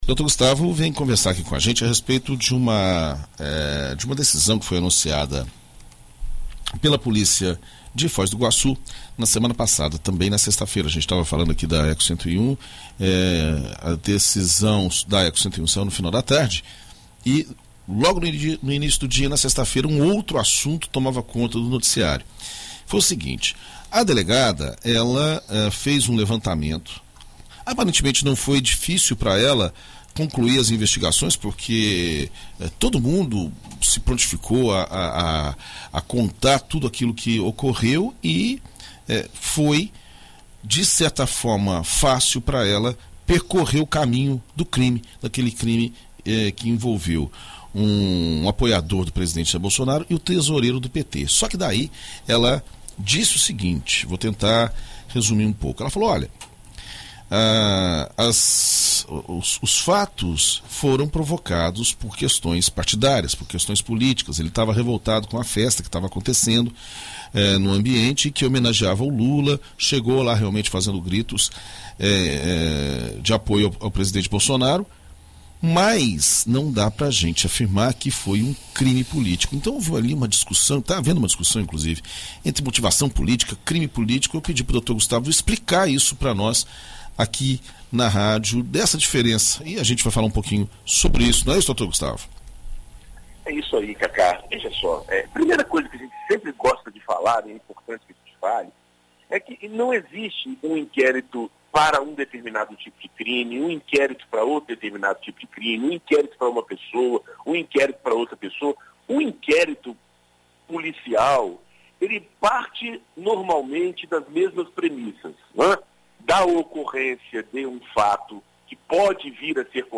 Na coluna Direito para Todos desta segunda-feira (18), na BandNews FM Espírito Santo